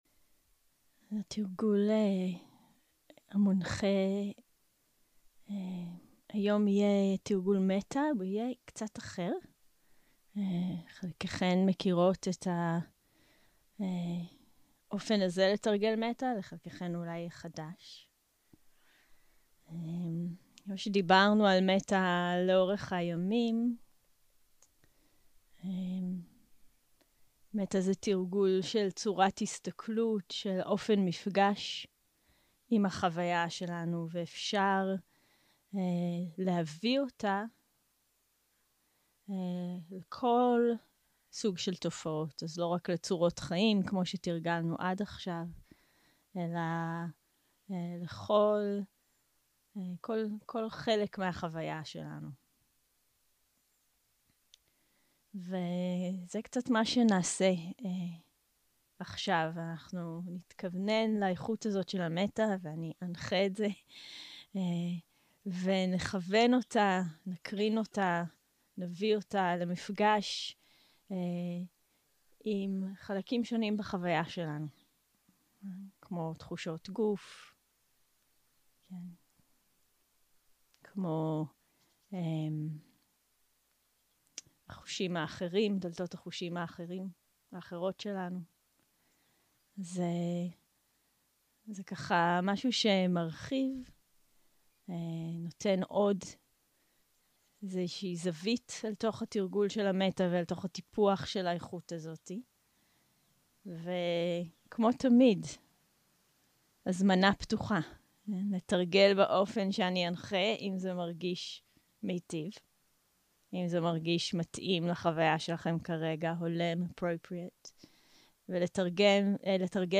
יום 6 - הקלטה 15 - צהרים - מדיטציה מונחית - מטא לתופעות.
0:00 0:00 סוג ההקלטה: Dharma type: Guided meditation שפת ההקלטה: Dharma talk language: Hebrew